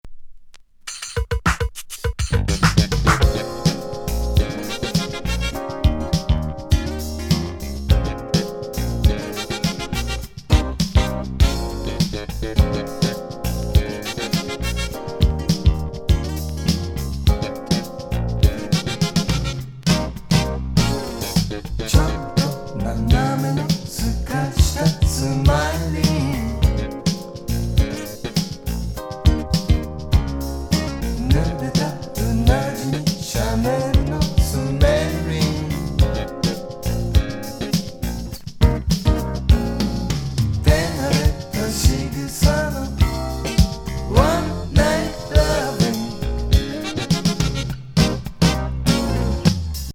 全編リゾート気分なフワフワ アレンジ・ブリージンAOR！
和レアリック・スムース・メロウ・ディスコ
トロトロ・まどろみ